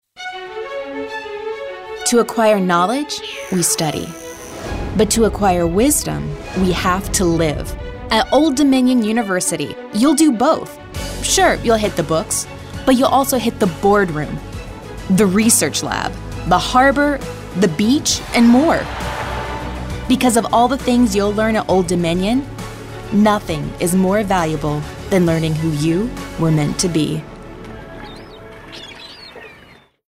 anti-announcer, compelling, confident, cool, inspirational, millennial, motivational, young adult